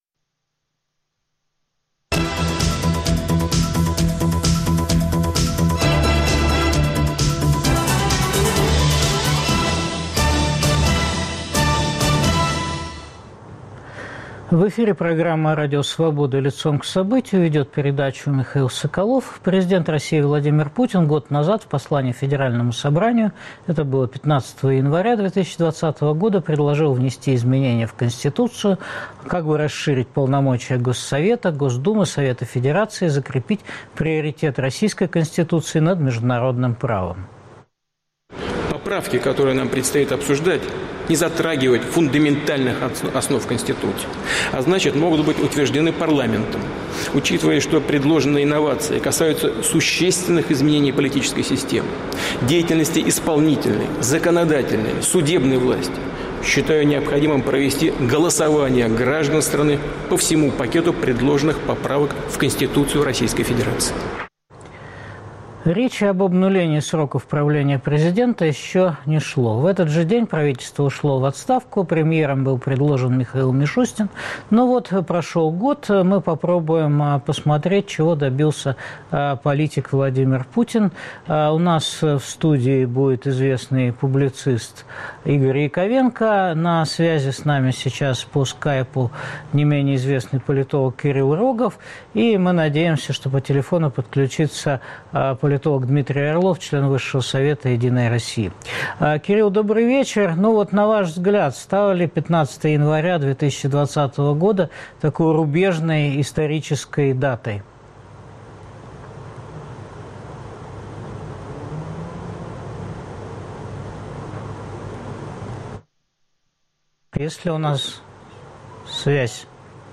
Чего за год добились постановщики конституционного переворота? Удалось ли заморозить страну надолго или навсегда? Обсуждают политолог